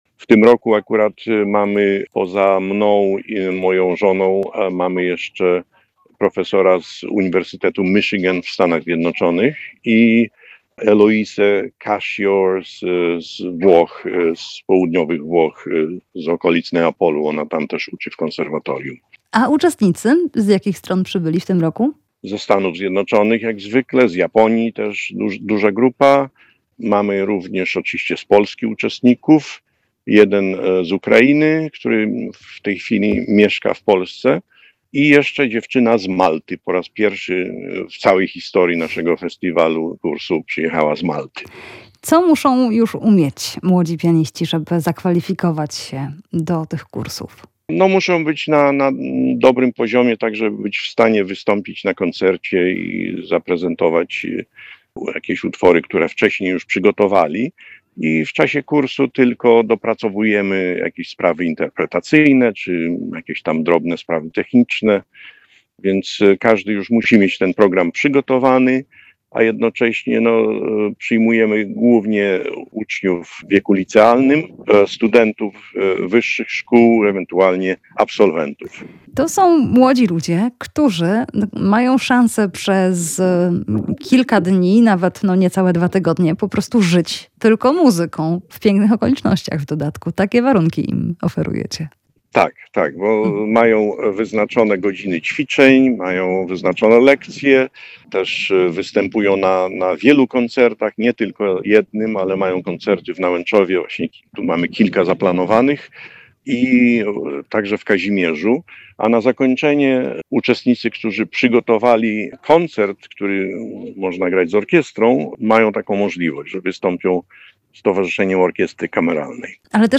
Koncerty pianistów w Nałęczowie i w Kazimierzu [POSŁUCHAJ ROZMOWY]